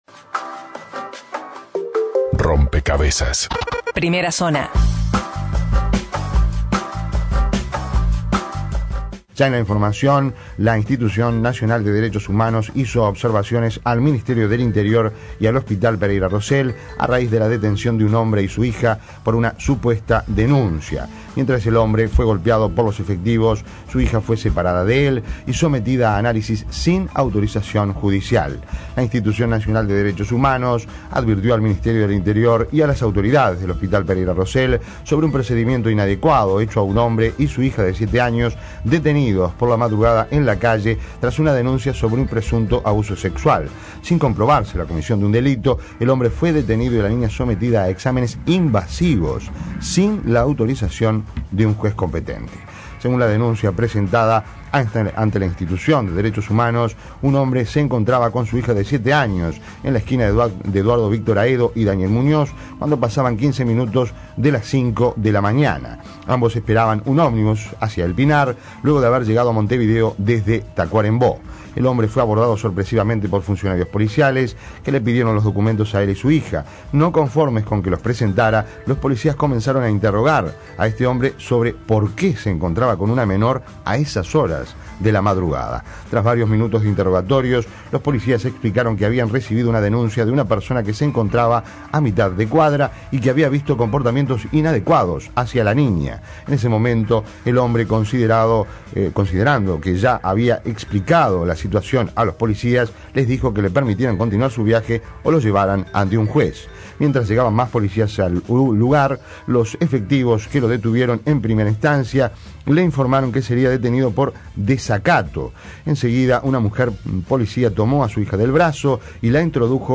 El integrante de la INDDHH, Juan Faroppa, conversó con Rompkbzas al igual que el padre que relató todo lo sucedido y anunció que iniciará acciones judiciales contra el Ministerio del Interior.
ENTREVISTA